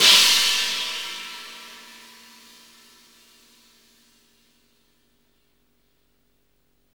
Index of /90_sSampleCDs/Roland - Rhythm Section/CYM_Cymbals 1/CYM_Cymbal menu
CYM CHINA07R.wav